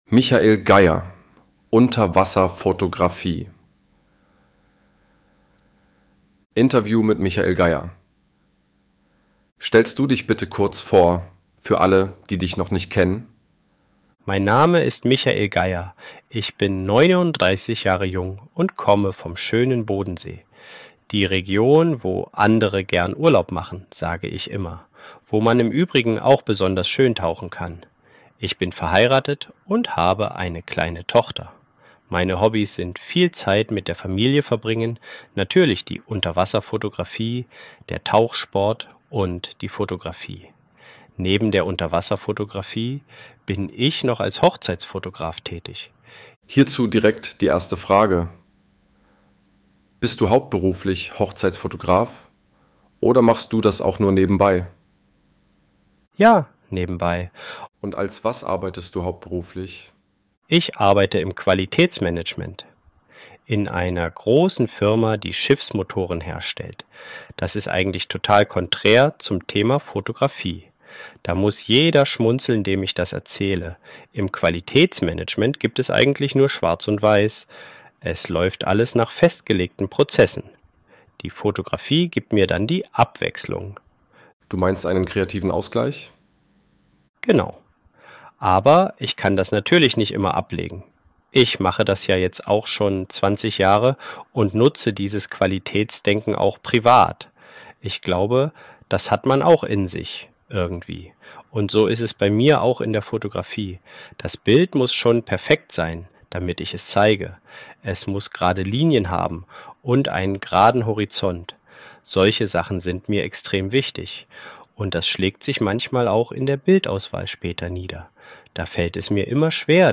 von KI generiert | Wildtierfoto Magazin #2 - Arten-vielfalt